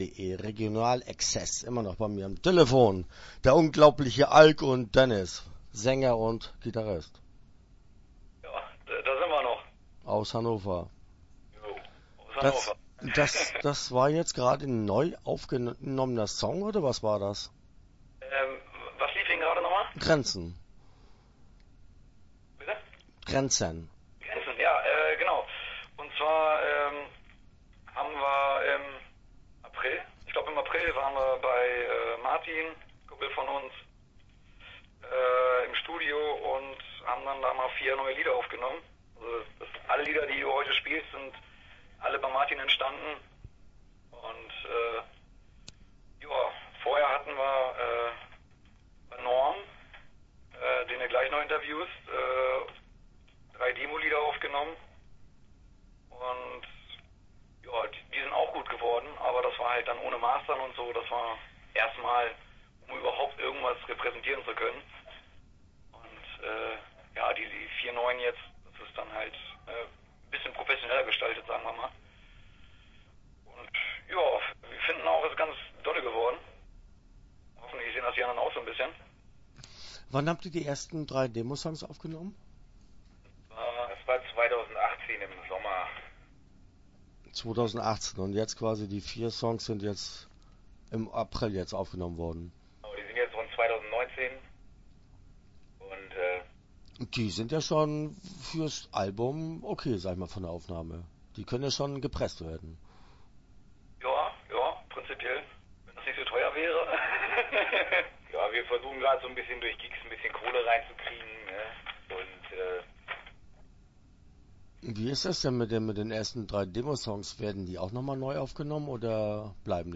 Start » Interviews » Regional Exzess